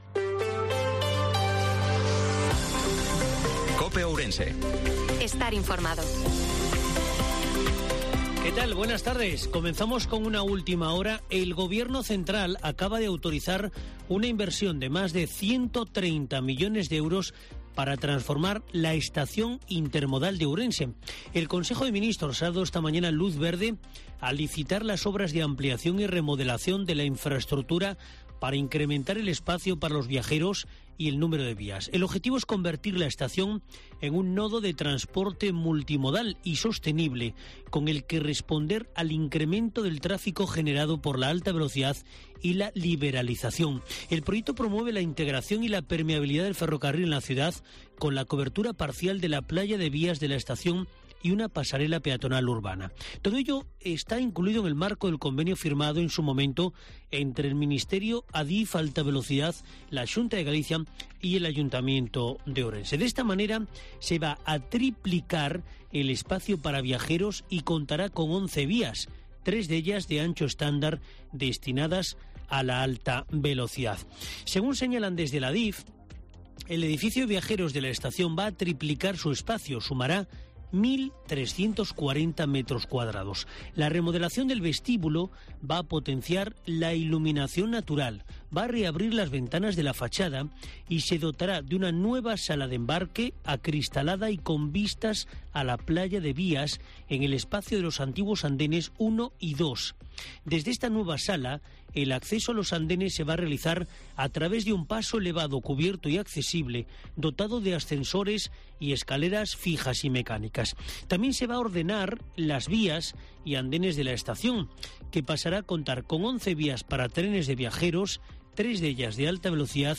INFORMATIVO MEDIODIA COPE OURENSE-16/05/2023